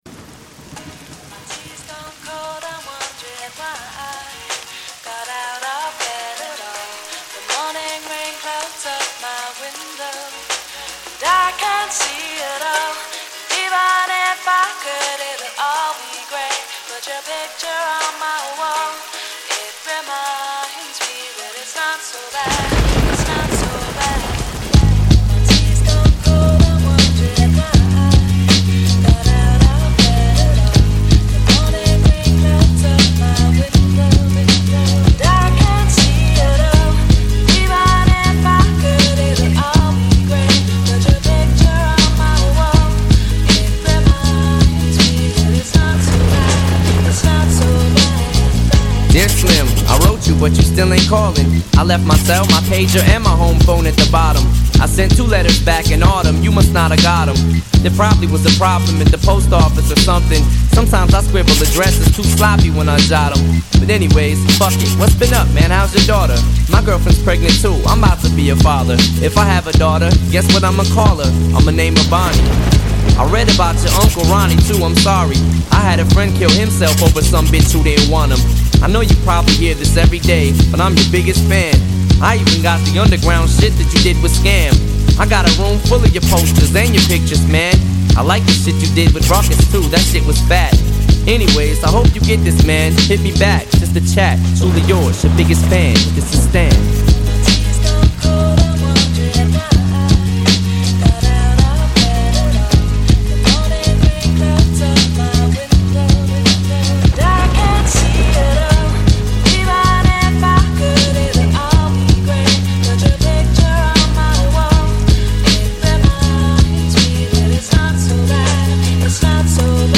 Жанр: Rap/Hip Hop